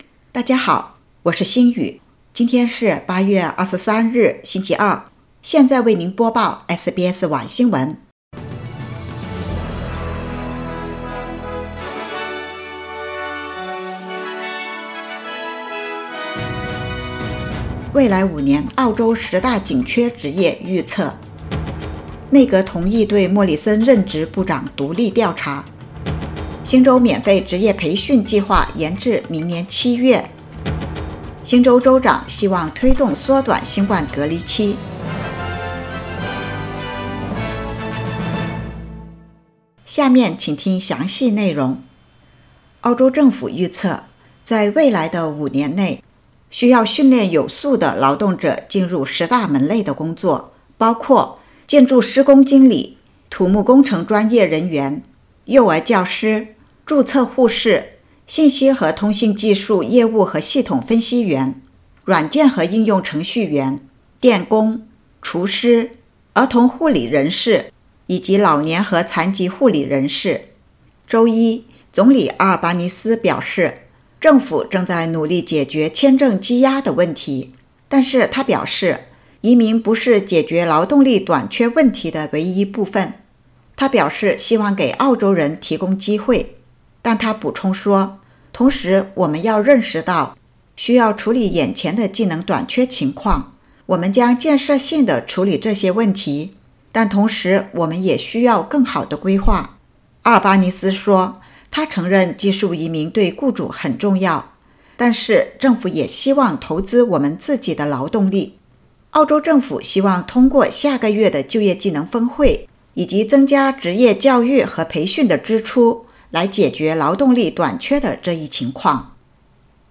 SBS晚新闻（2022年8月23日）